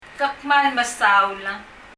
１．　フォーマルな表現　　　Ke kmal mesaul　　　[kɛ(ə) kməl mɛsaul]
発音　あるいは